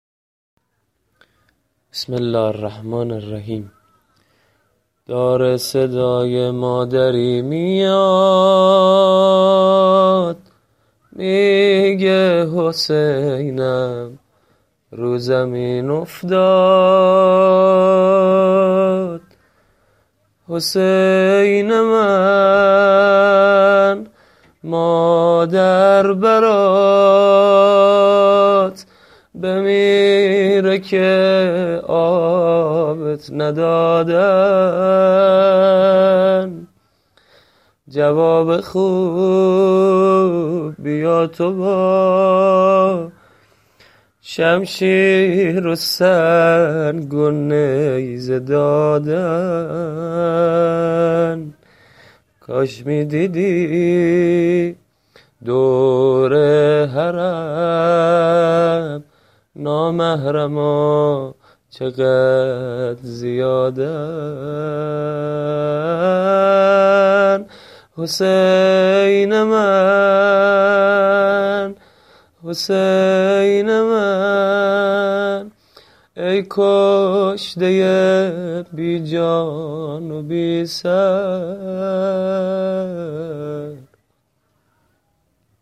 زمزمه ی شب اول